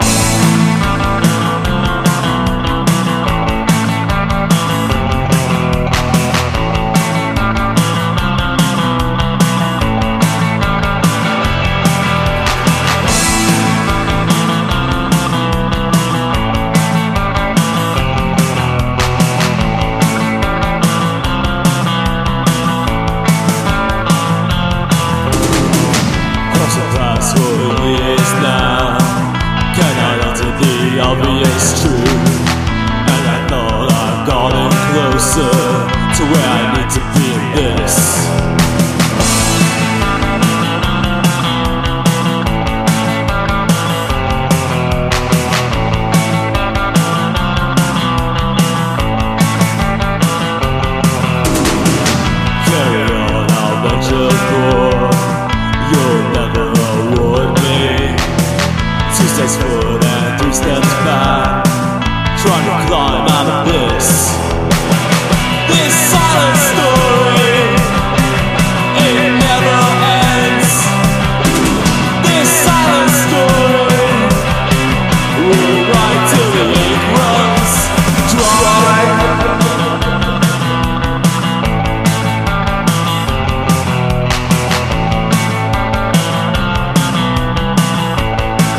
ROCK / 70'S / FUNKY ROCK / A.O.R.
ファンキー・ロックな76年作！